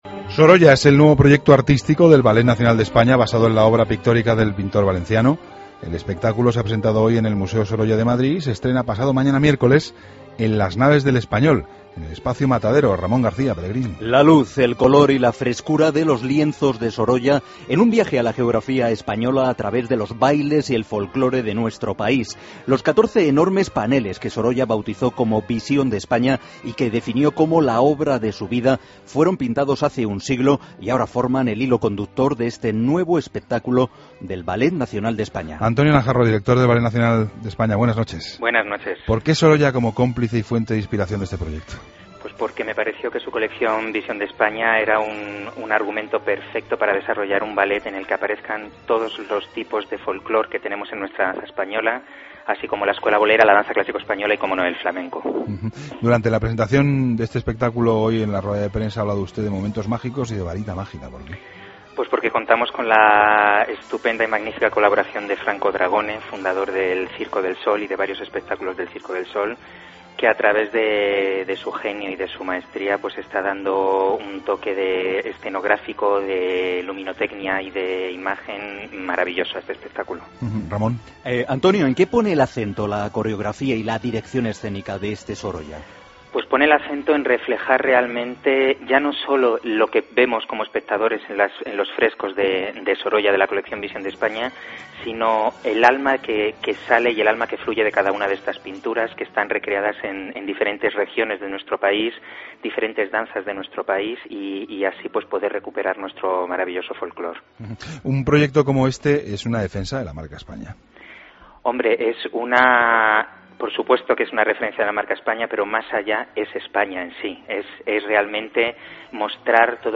AUDIO: Entrevista a Antonio Najarro, director del Ballet Nacional.